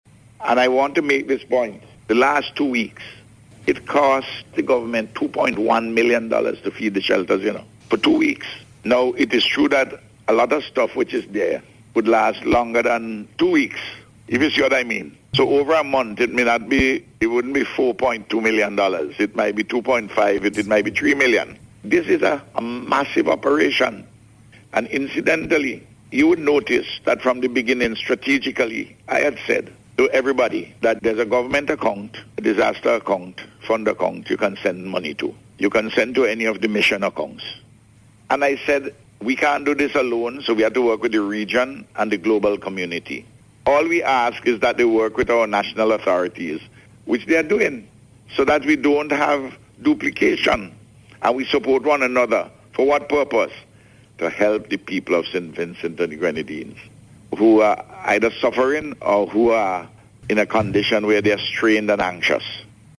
This is according to Prime Minister Dr. Ralph Gonsalves, as he provided an update on the ongoing National Relief Effort on Radio yesterday.